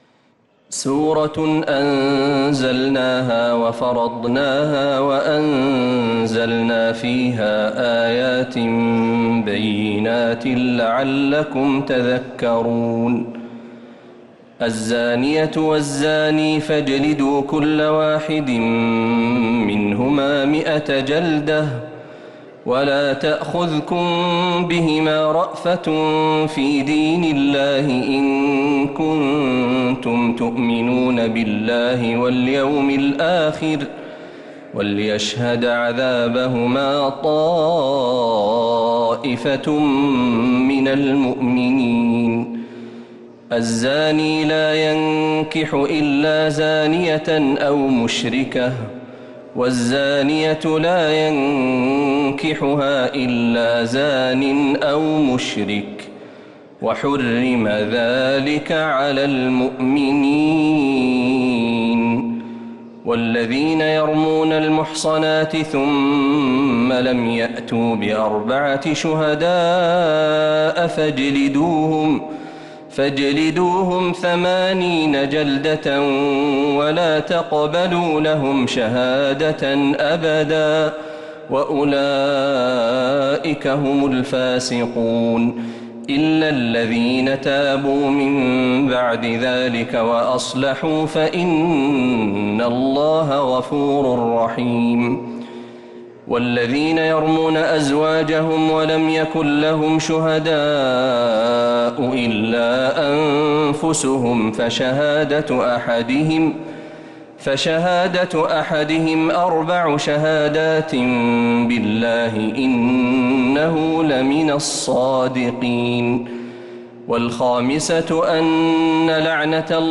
سورة النور كاملة من تهجد الحرم النبوي للشيخ محمد برهجي | رمضان 1445هـ > السور المكتملة للشيخ محمد برهجي من الحرم النبوي 🕌 > السور المكتملة 🕌 > المزيد - تلاوات الحرمين